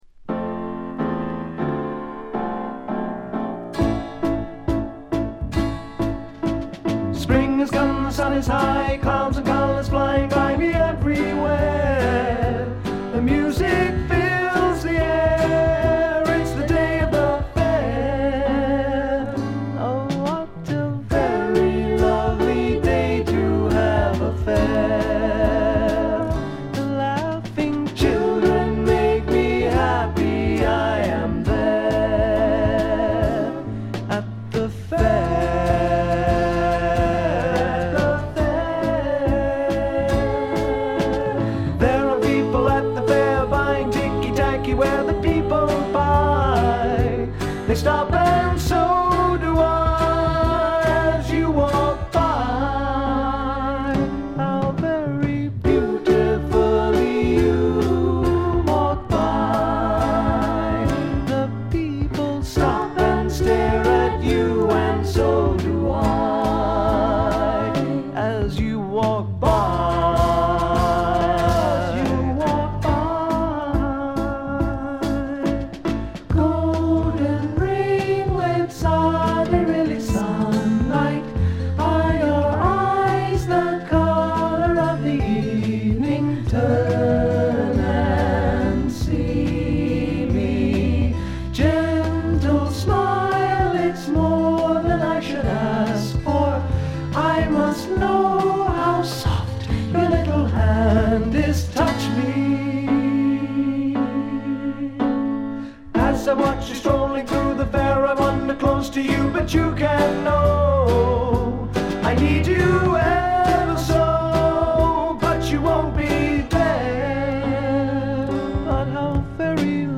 ごくわずかなノイズ感のみ。
で内容はというと英米の良さを併せ持った素晴らしすぎるフォーク／フォークロックです。
試聴曲は現品からの取り込み音源です。